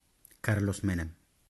Carlos Saúl Menem (Spanish pronunciation: [ˈkaɾlos ˈmenen]
ES-ar-Carlos_Menem.ogg.mp3